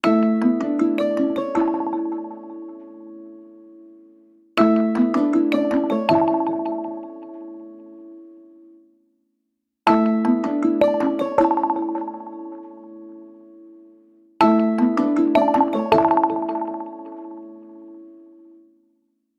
Звуки будильника на Android 7 для Google Pixel Argon